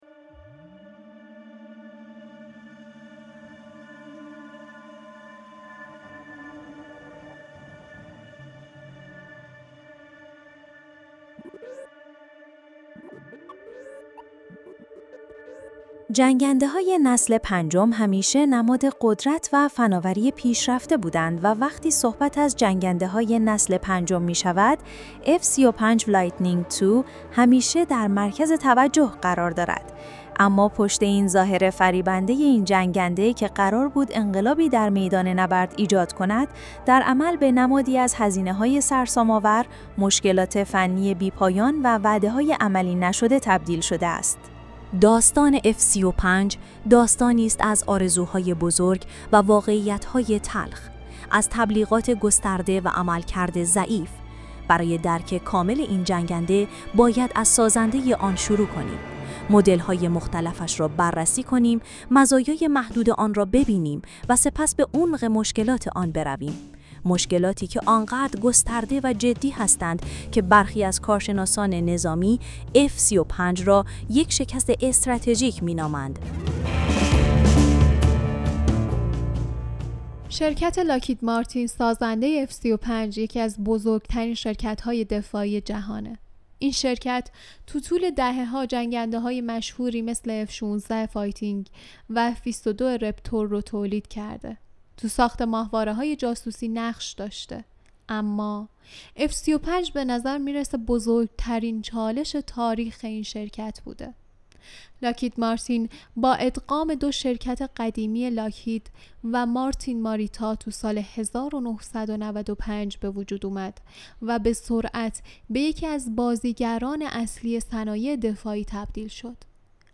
اپیزودهای ما در این شروع جدید، برخلاف فصل قبلی که با دیالوگی بین انسان و هوش مصنوعی (جمینی) شروع میشد، این بار با مونولوگی از یک هوش مصنوعی متفاوت (دیپ سیک ) شروع می‌شود. راوی ما در هر اپیزود، به همراه یک دستیار هوش مصنوعی به بیان موضوعات و پدیده ‌ های مختلف در حوزه ‌ ی علم و فناوری، تاریخ، فرهنگ و... می ‌ پردازد.